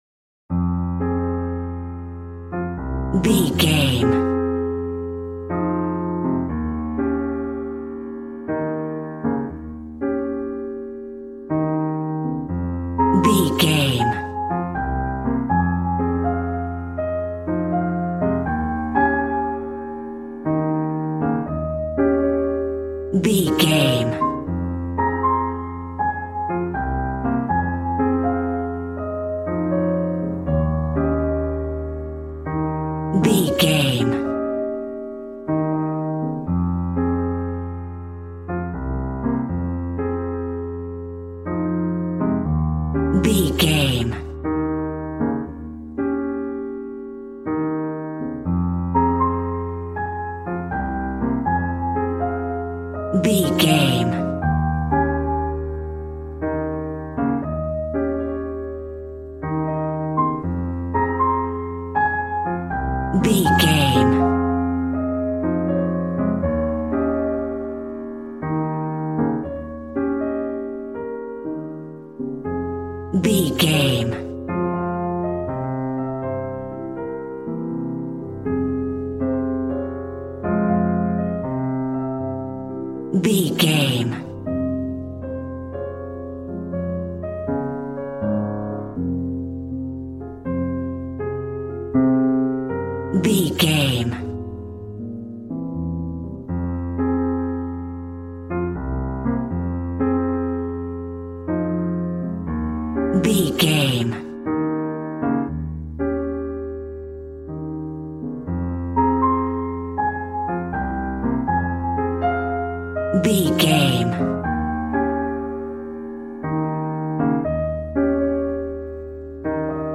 Smooth jazz piano mixed with jazz bass and cool jazz drums.
Ionian/Major
A♭
drums